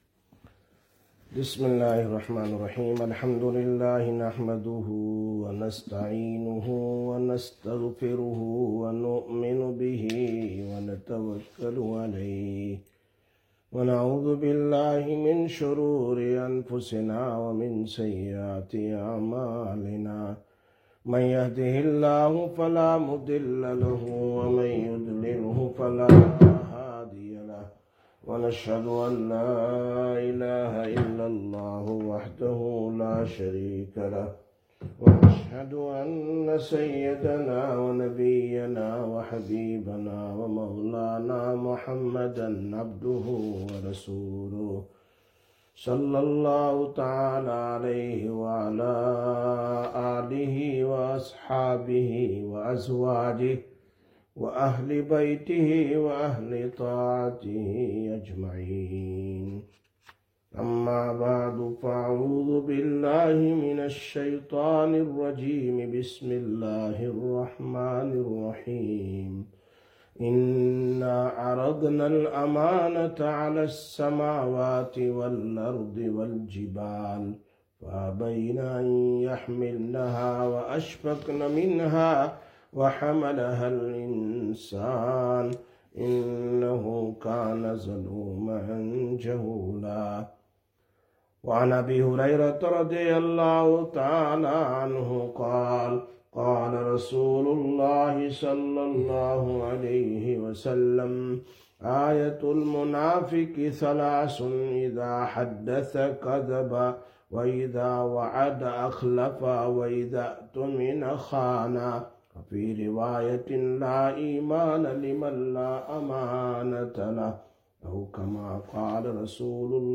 24/12/2025 Sisters Bayan, Masjid Quba